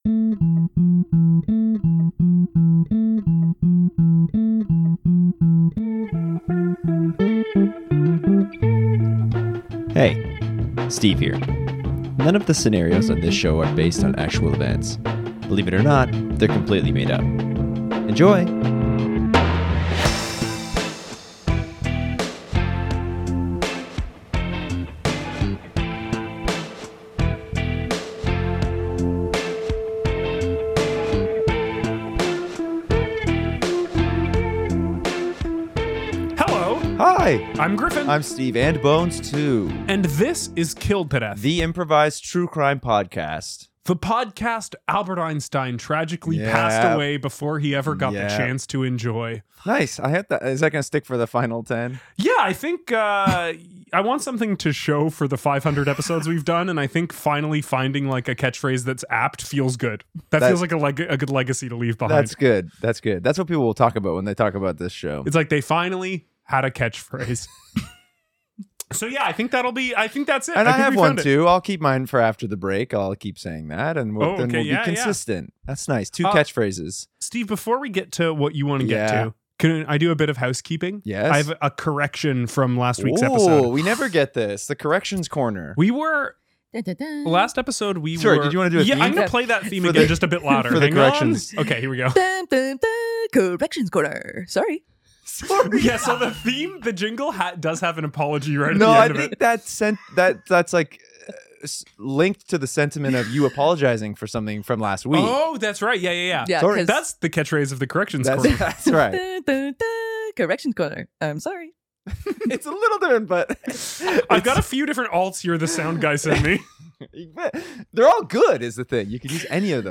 The Improvised True Crime Podcast. Every week, one case is analyzed and solved with the help of a guest.